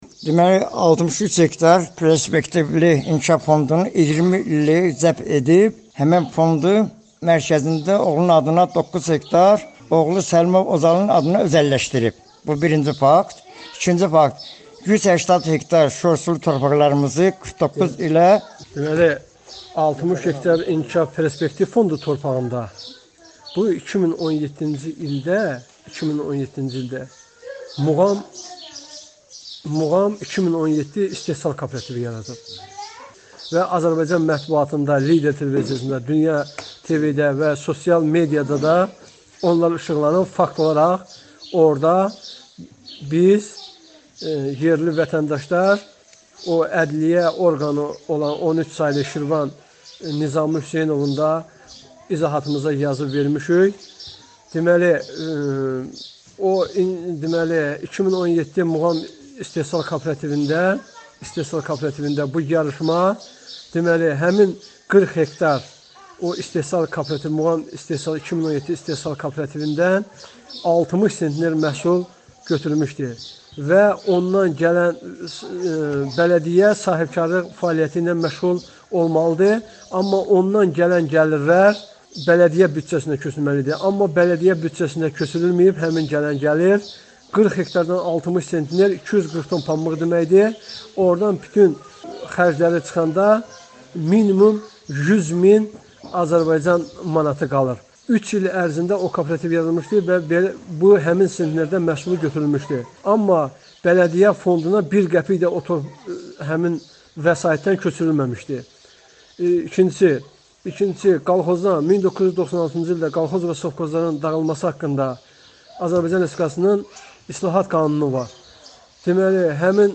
Kənd sakinləri: Bələdiyyə sədri qanunları pozur
Şikayətçi kənd sakini